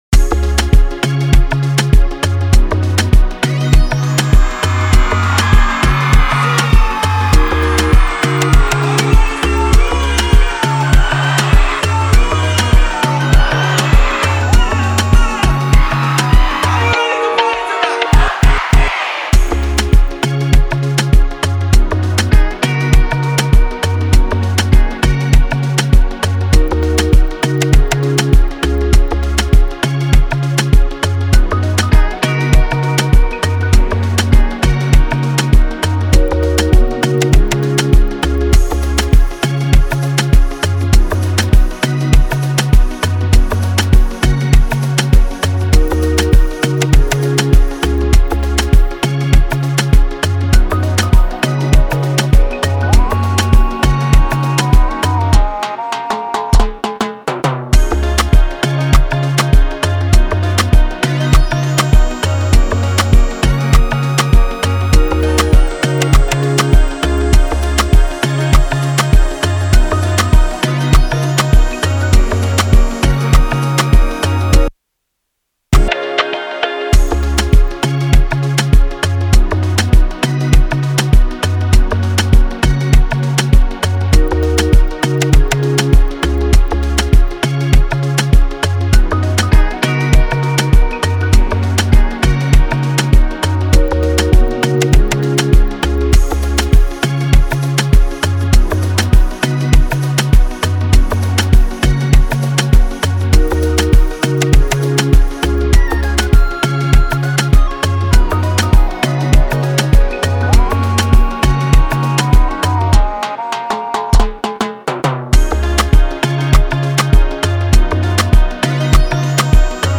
2025 in Dancehall/Afrobeats Instrumentals